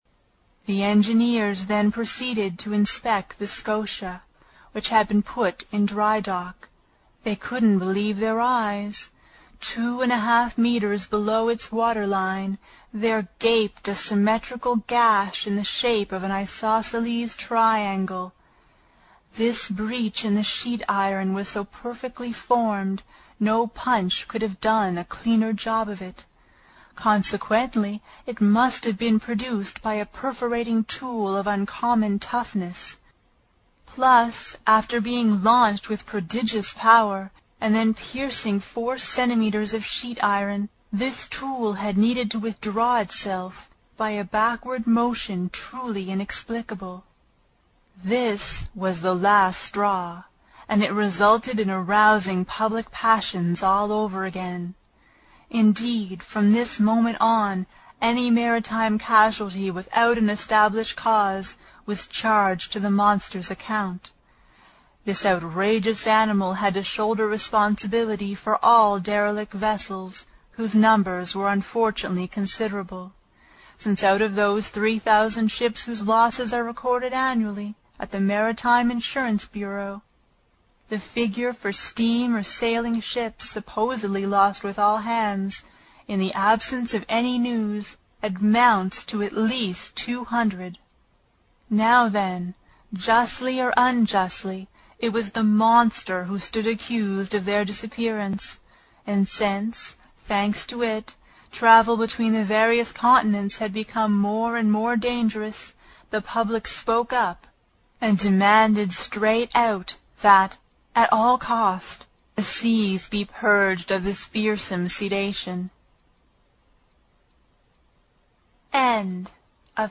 在线英语听力室英语听书《海底两万里》第11期 第1章 飞走的暗礁(11)的听力文件下载,《海底两万里》中英双语有声读物附MP3下载